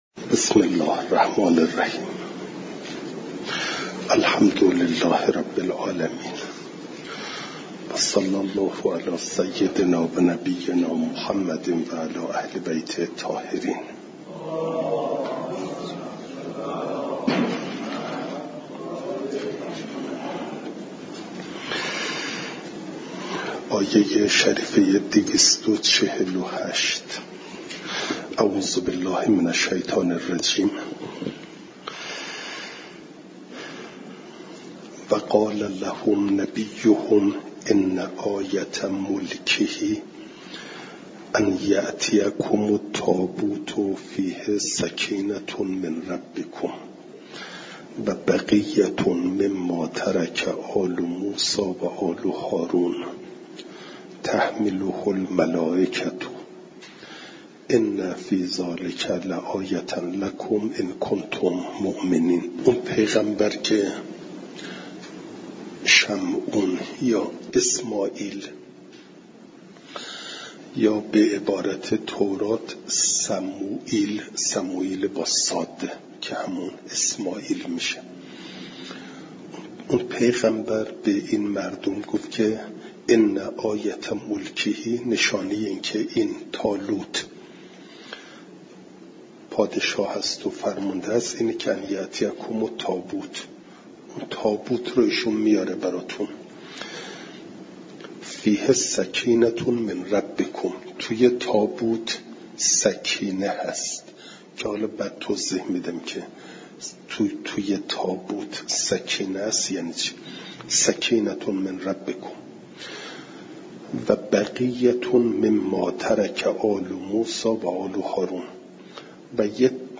فایل صوتی جلسه دویست و بیست و ششم درس تفسیر مجمع البیان